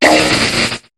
Cri d'Armulys dans Pokémon HOME.